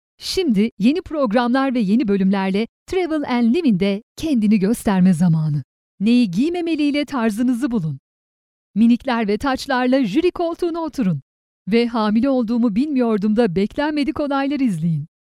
Turkish Native Female Voice Over
Kein Dialekt
Sprechprobe: Sonstiges (Muttersprache):